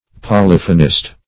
Search Result for " polyphonist" : The Collaborative International Dictionary of English v.0.48: Polyphonist \Po*lyph"o*nist\, n. 1. A proficient in the art of multiplying sounds; a ventriloquist.